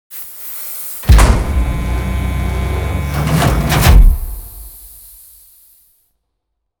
Scrape2.wav